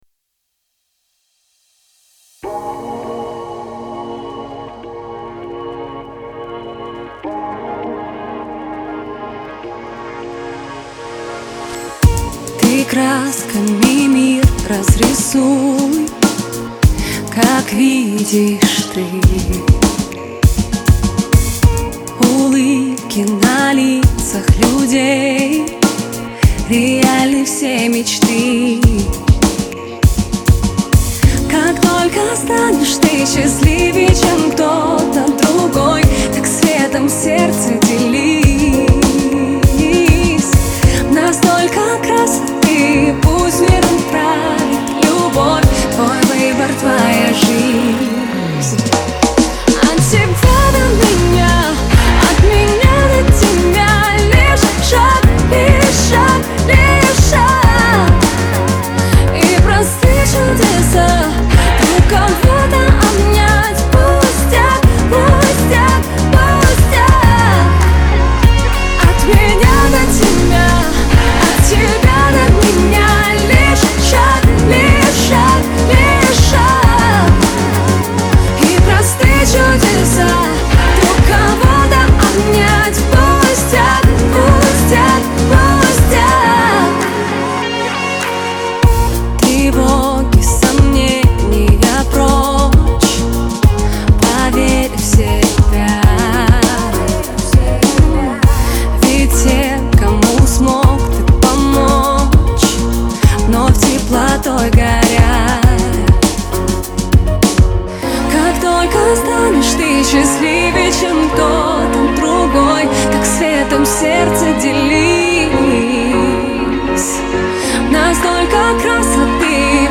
исполненная в жанре поп-рок.